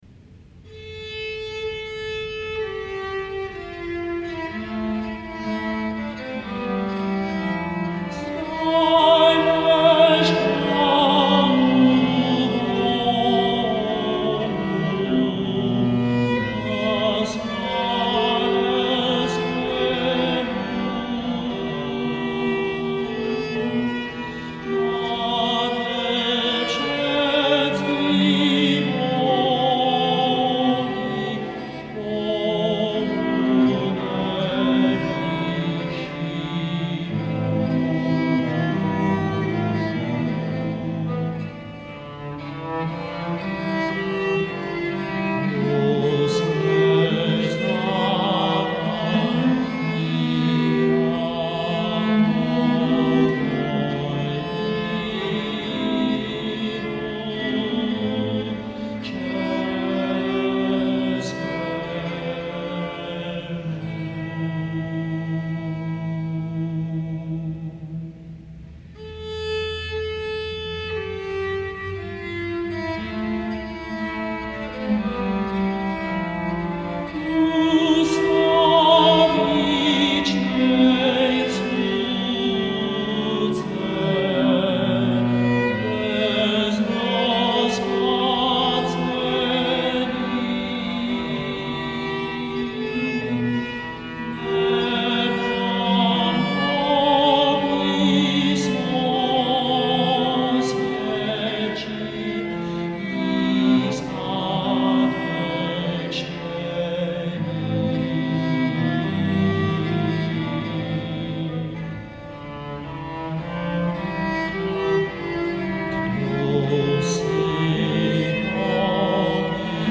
A decidedly protestant text set in a 3-voice Tenorlied displaying lots of Slavic soul . . . from a 16th century Hussite songbook.